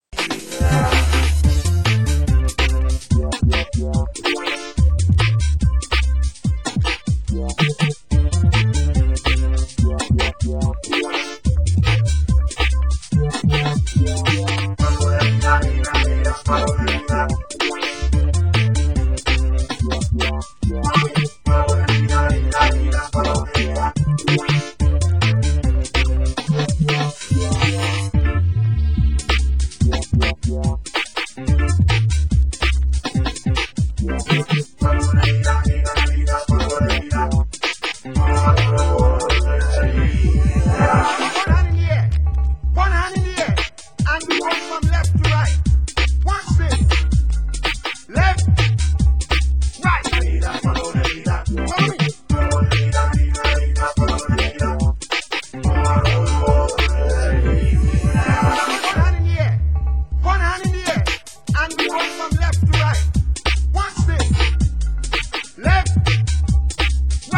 Genre: UK Garage
TREBLE VOCAL ,DUB